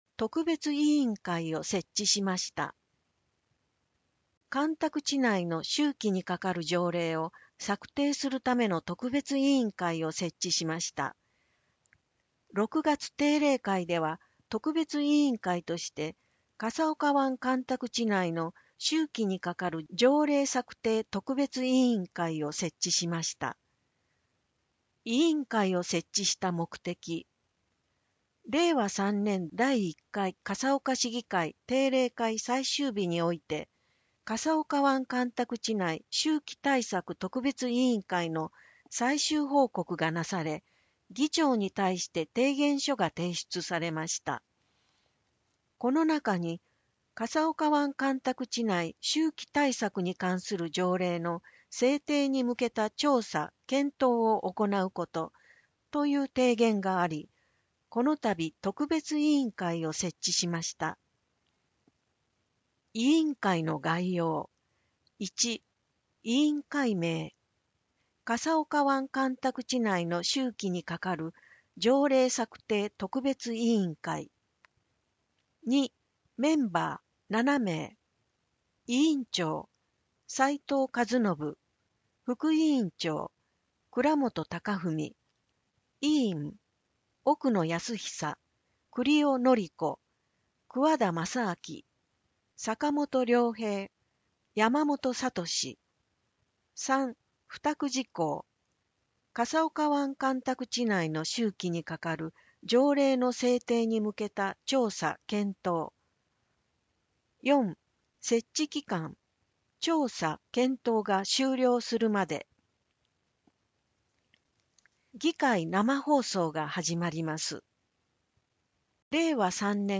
市議会だより169号（音訳版）
（音声データ提供 笠岡音訳の会） 50分15秒